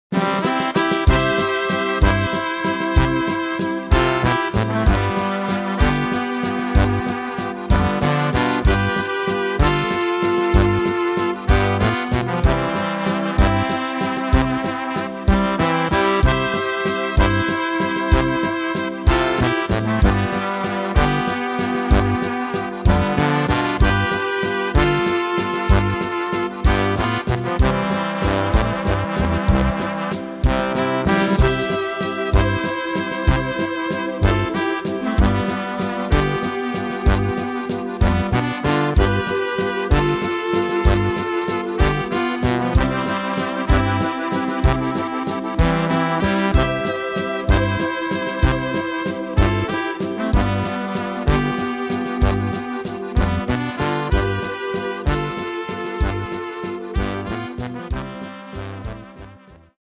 Tempo: 190 / Tonart: C-Dur